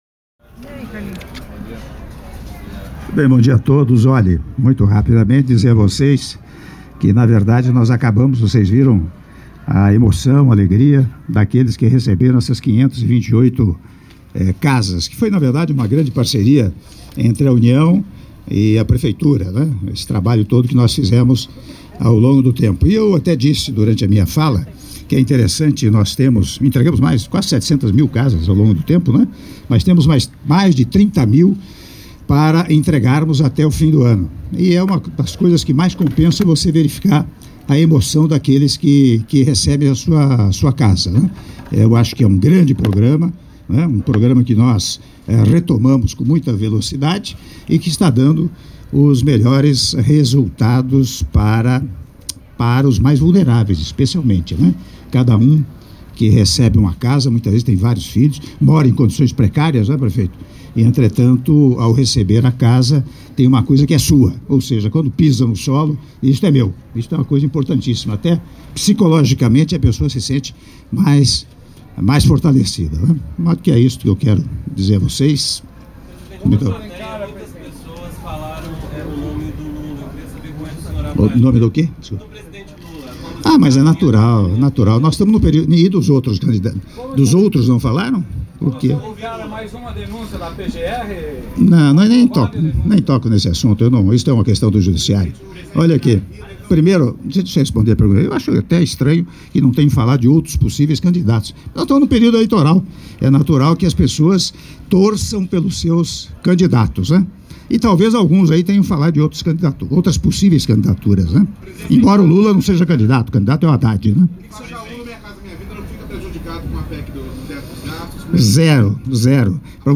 Áudio da Entrevista coletiva concedida pelo Presidente da República, Michel Temer, após cerimônia de Entrega de 528 Unidades Habitacionais do Condomínio Residencial Flamboyant I, II e III - Guaratinguetá/SP- (03min29s)